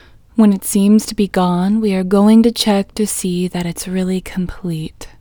IN Technique First Way – Female English 21